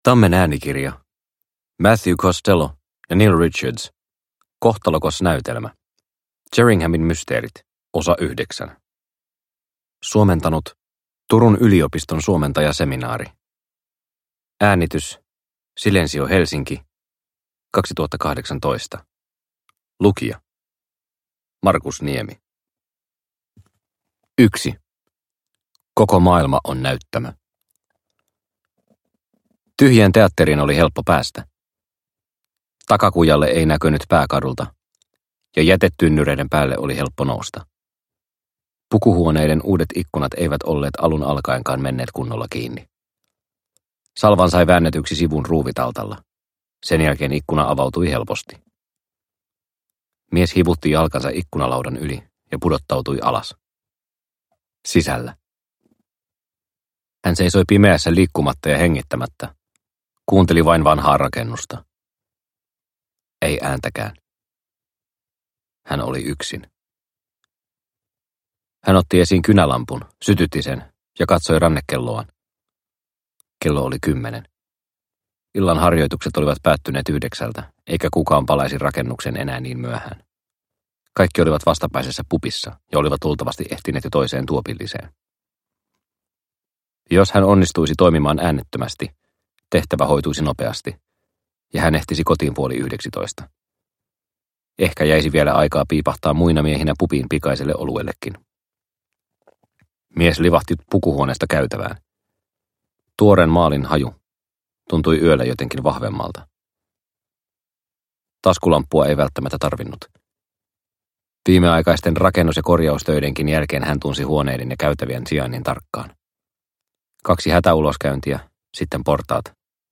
Kohtalokas näytelmä – Ljudbok – Laddas ner